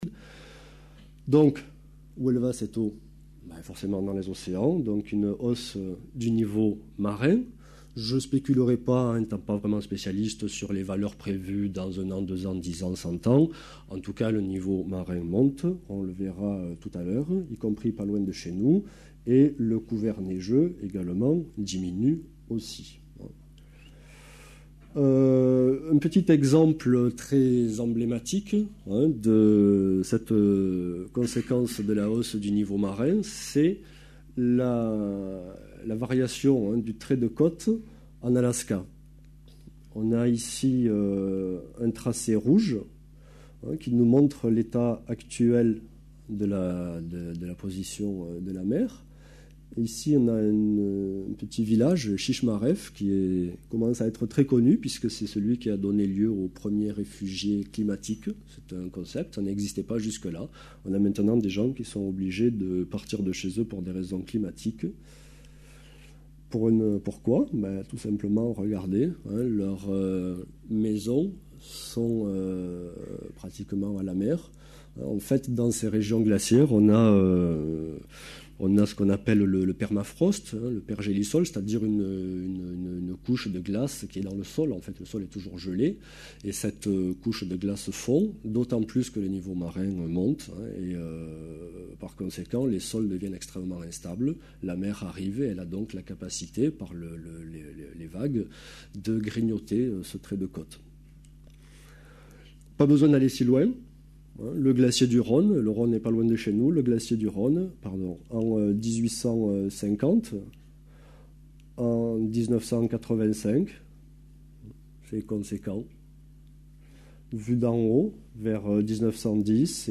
Un conférence de l'UTLS au Lycée